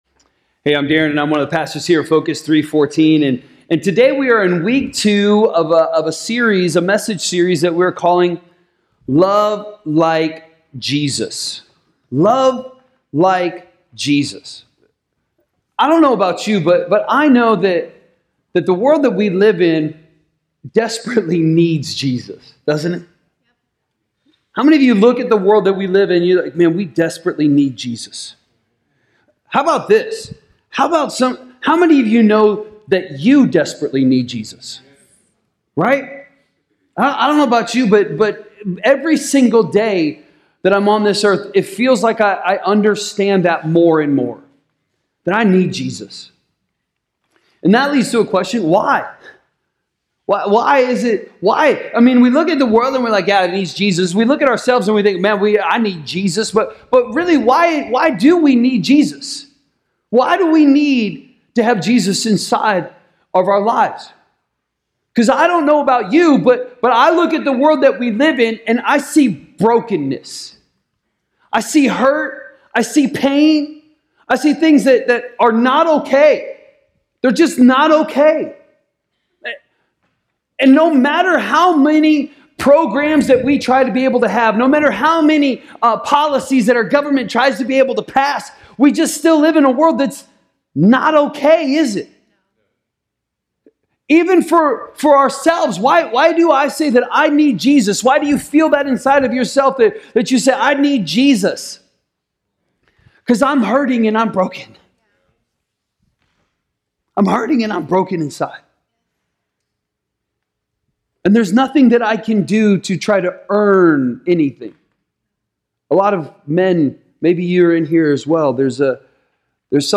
A message from the series "Love Like Jesus."